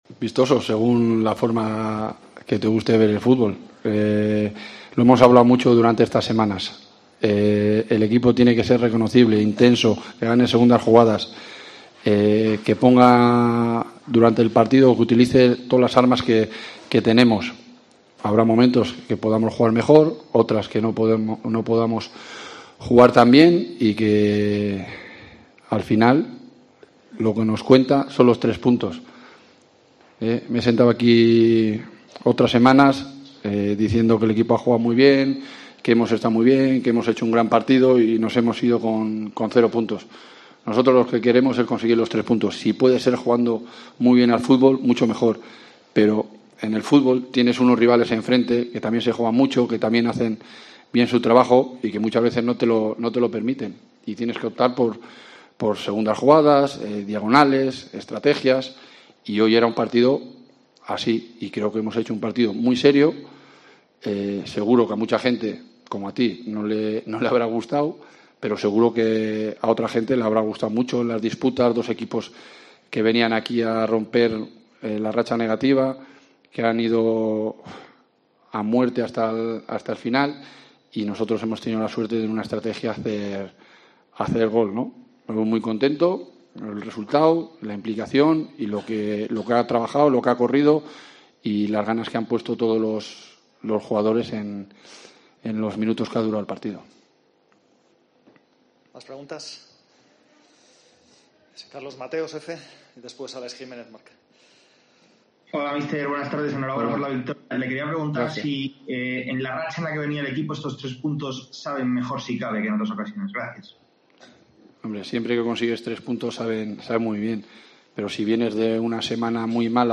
AUDIO: Escucha aquí las declaraciones